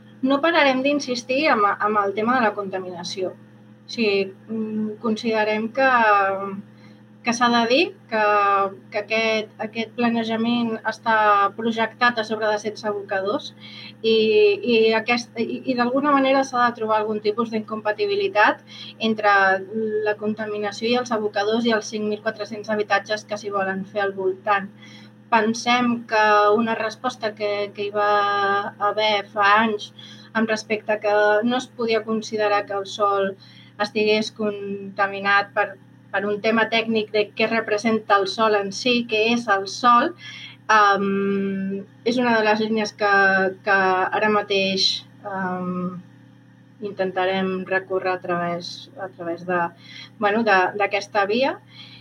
Declaracions
en roda de premsa